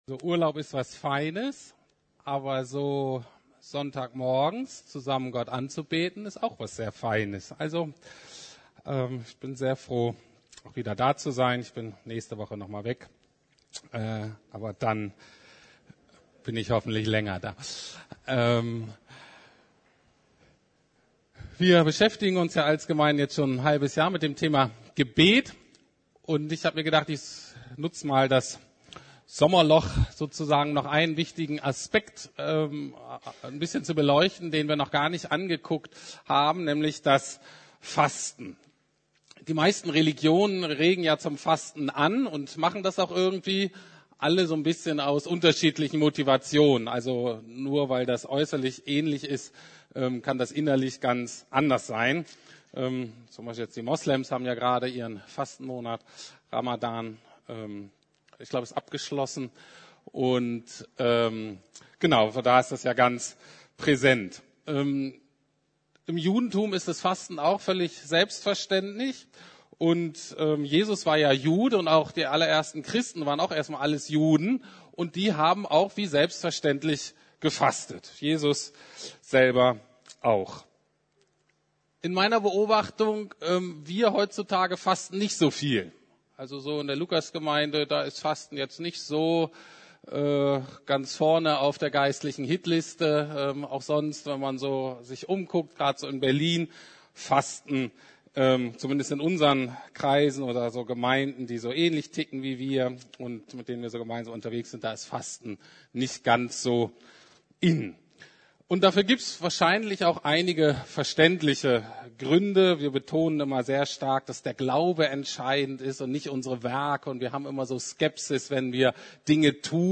Gebet und Fasten ~ Predigten der LUKAS GEMEINDE Podcast